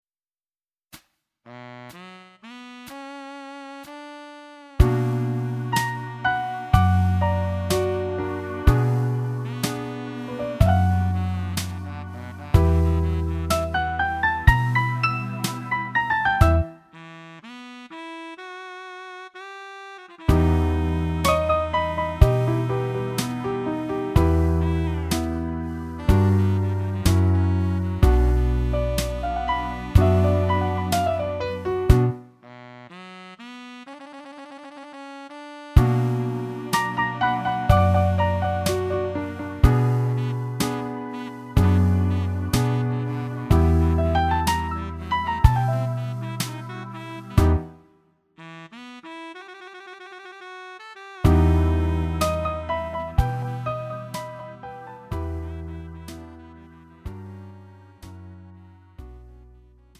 음정 -1키
장르 구분 Lite MR